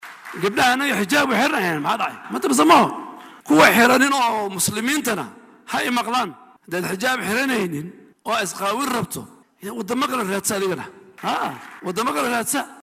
Wasiirka oo shalay ka hadlayay munaasabad lagu daahfurayay tartan quraan ayaa carrabka ku adkeeyay in haweenka iyo gabdhaha muslimiinta ay qasab ku tahay inay qaataan xijaabka isagana ay mas’uuliyad ka saaran tahay inuu arrintan baraarujiyo.